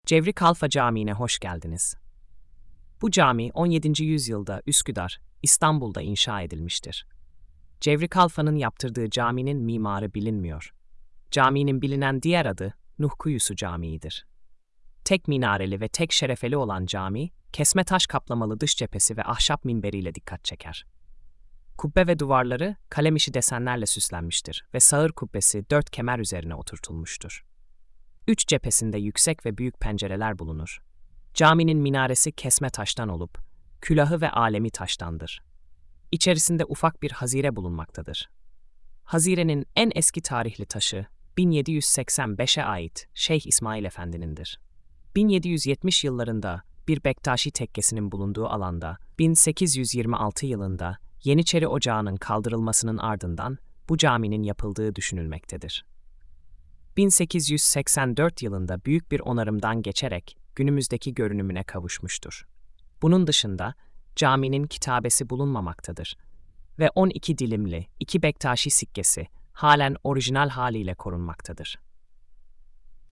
SESLİ ANLATIM: